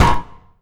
Paper Oneshot.wav